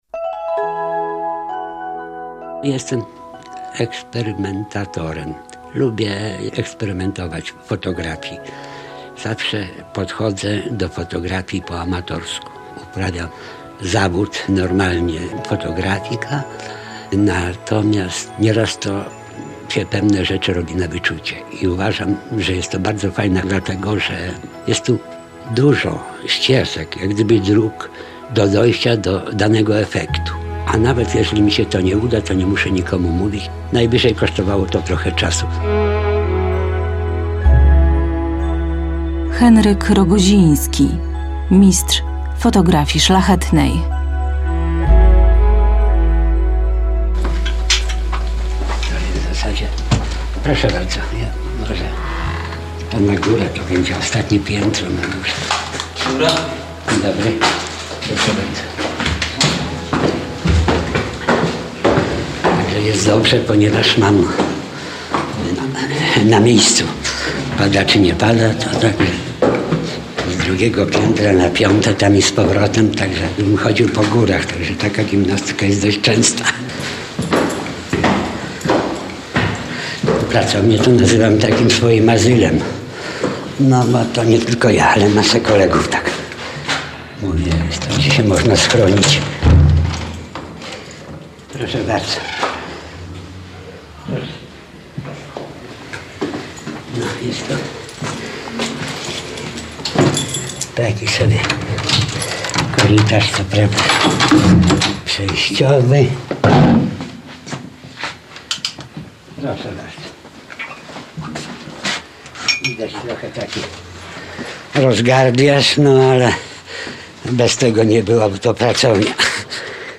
Radio Białystok | Reportaż | Białostoccy mistrzowie sztuk pięknych na falach eteru | 14.